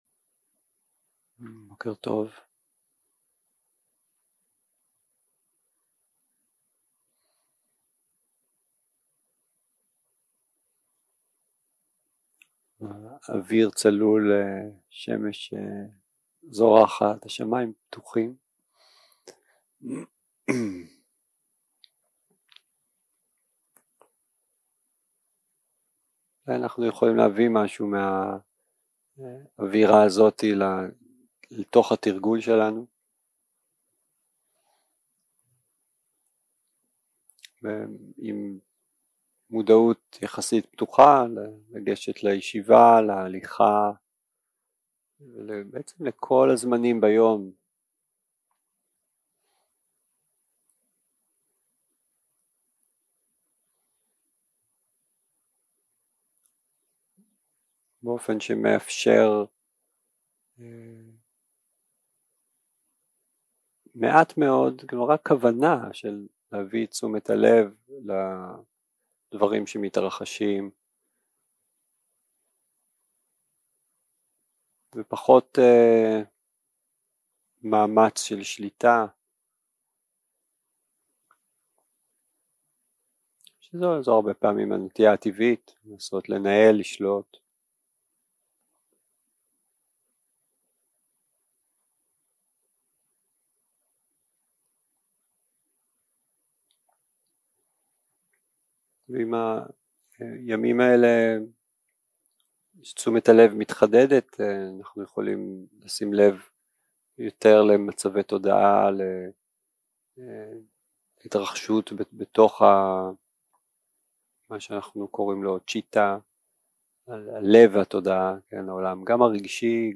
שיחת הנחיות למדיטציה שפת ההקלטה